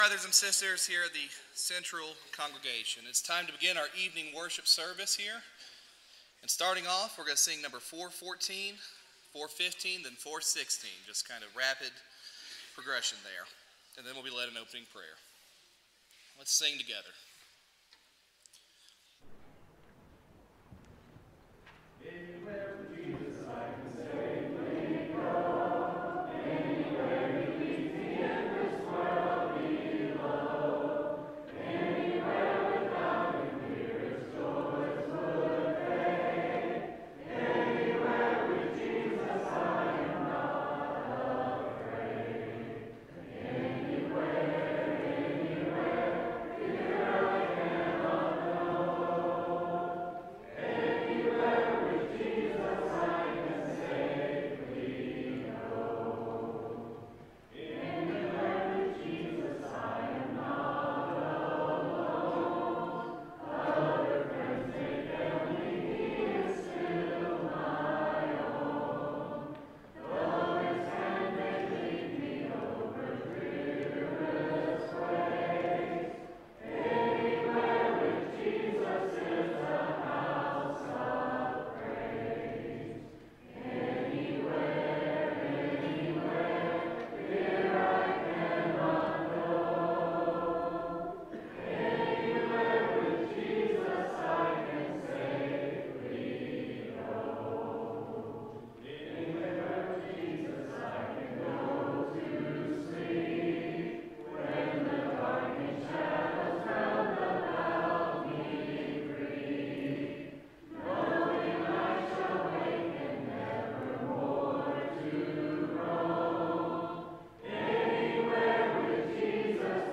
(Proverbs 3:5, English Standard Version) Series: Sunday PM Service